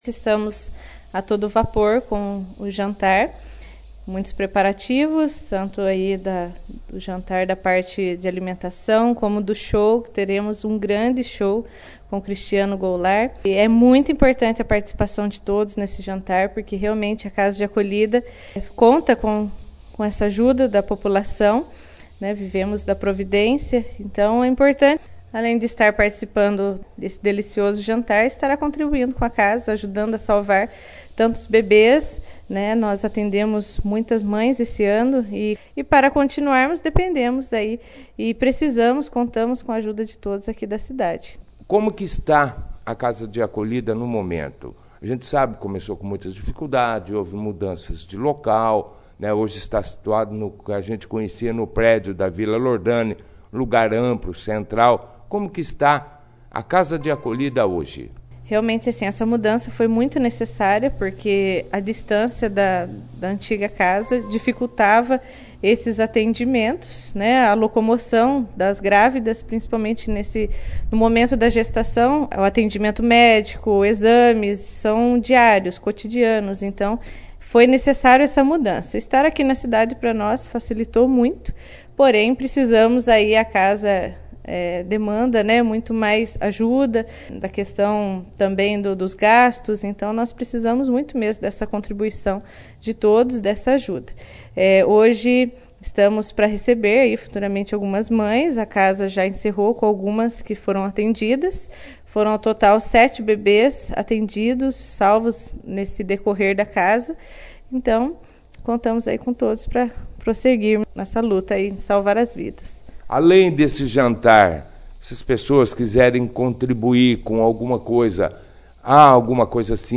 participou da 1ª edição do jornal Operação Cidade desta quinta-feira